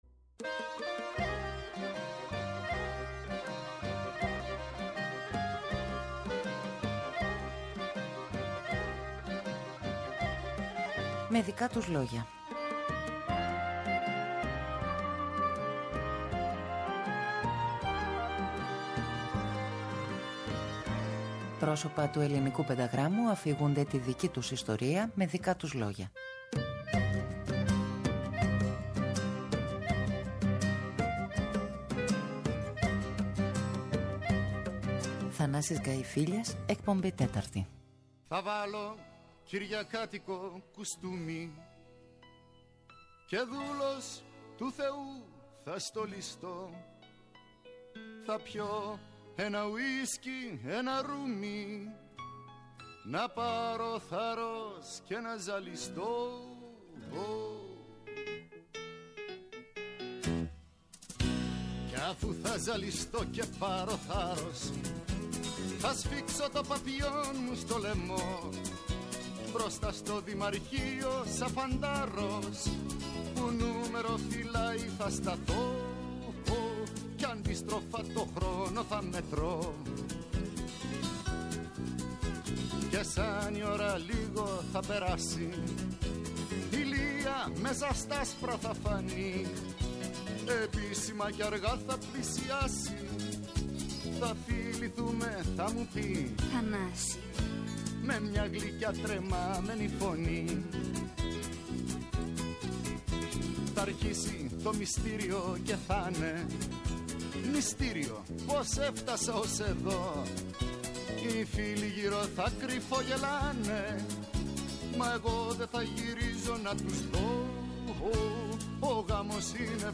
ΔΕΥΤΕΡΟ ΠΡΟΓΡΑΜΜΑ Με Δικα τους Λογια Αφιερώματα Μουσική Συνεντεύξεις